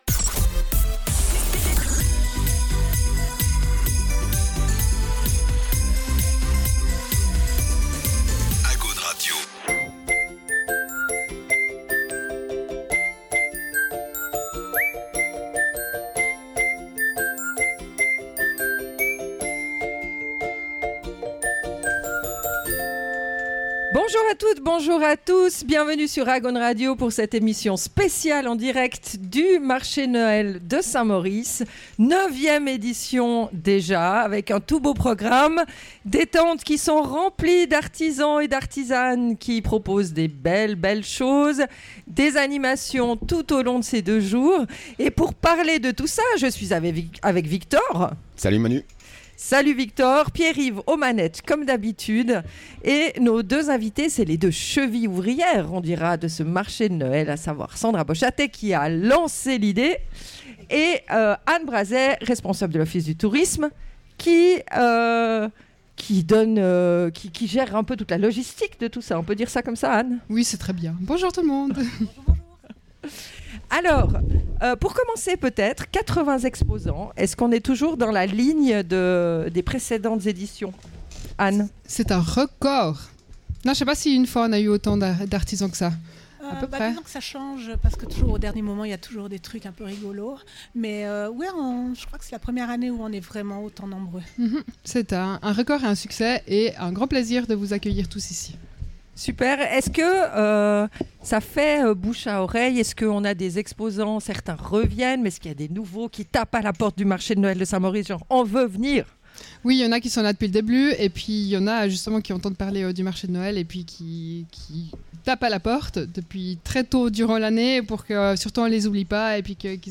On the Road Agaune en direct du Marché de Noël de Saint-Maurice.